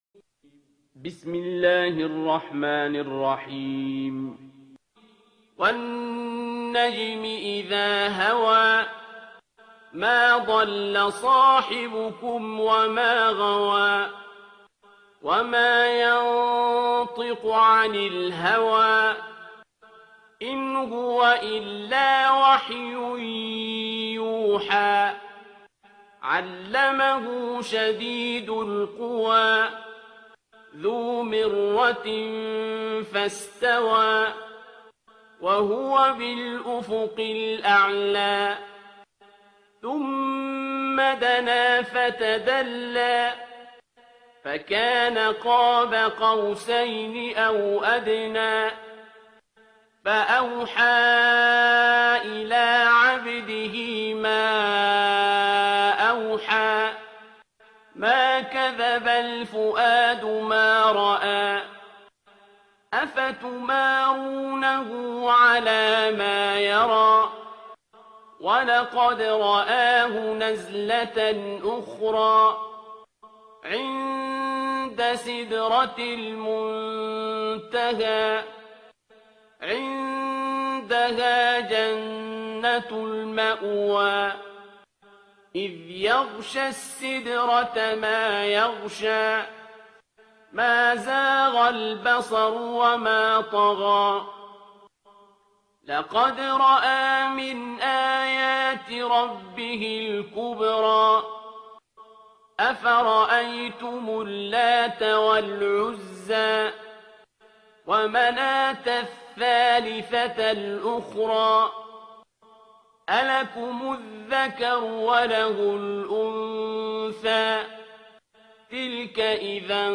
ترتیل سوره نجم با صدای عبدالباسط عبدالصمد
053-Abdul-Basit-Surah-An-Najm.mp3